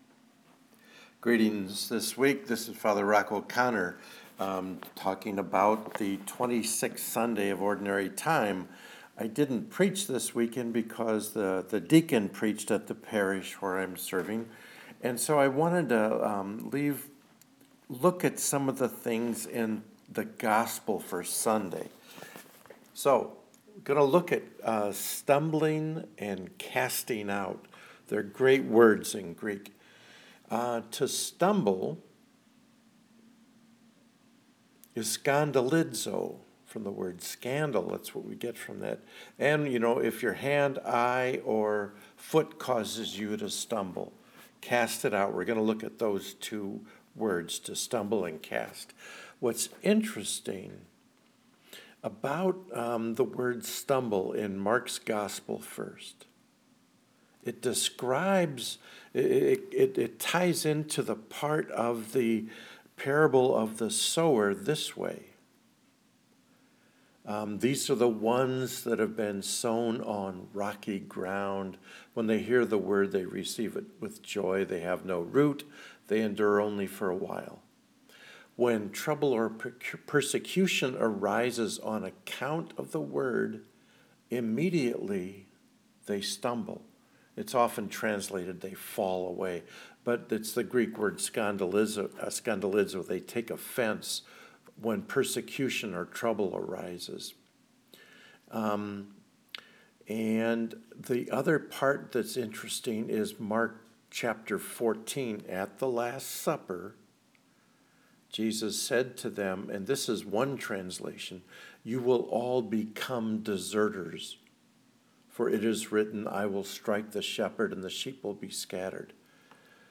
POSTS: Video Commentaries & Homilies (Audio)